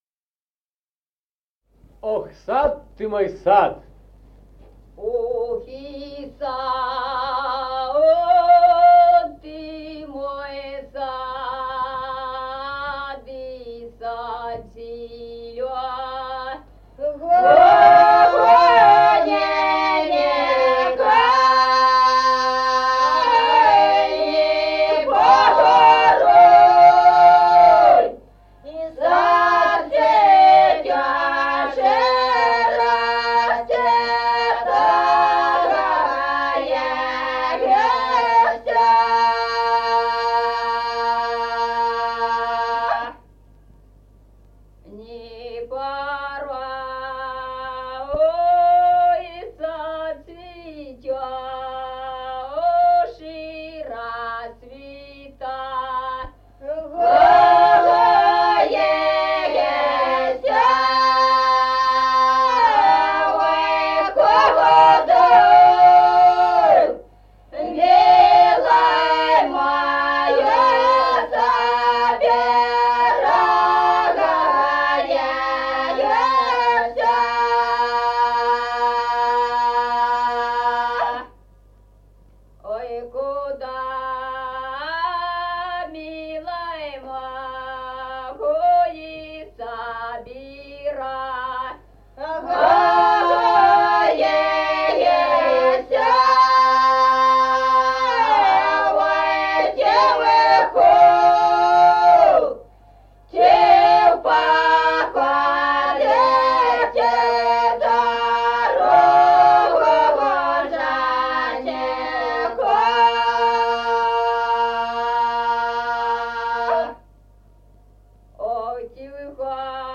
Музыкальный фольклор села Мишковка «Ох, сад, ты мой сад», воинская.